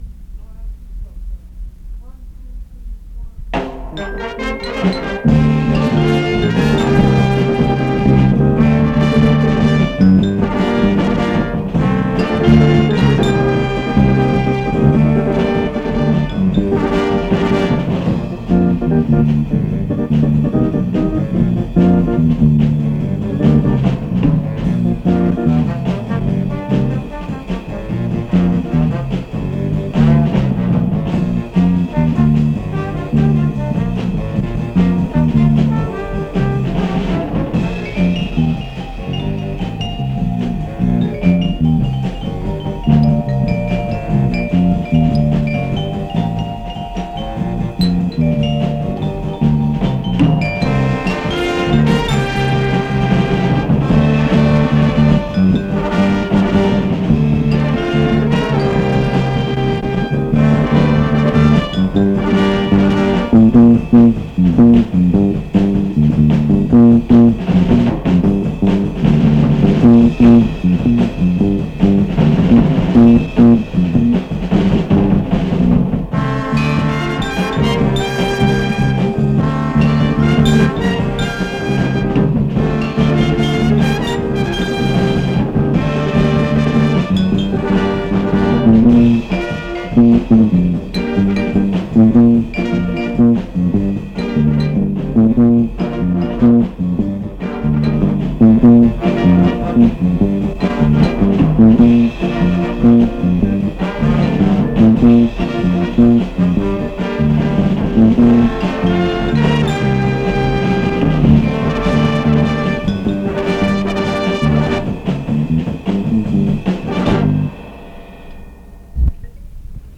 washington high school: 1969 summer jazz band